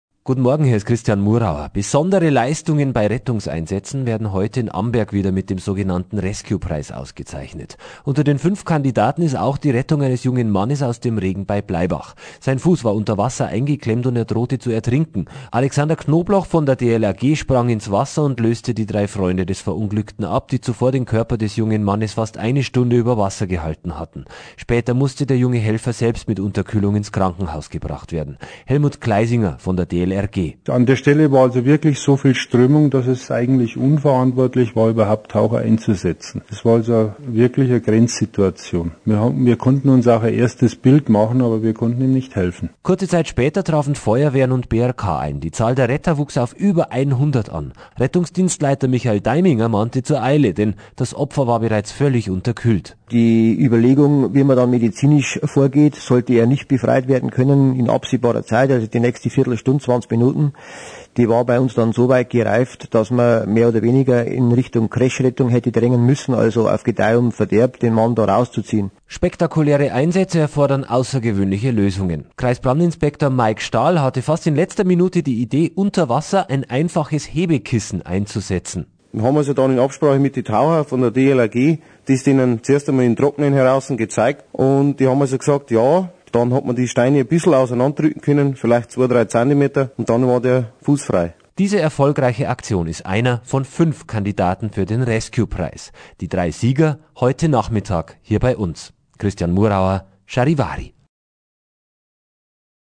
Bericht vor der Preisverleihung (1MB MP3-File)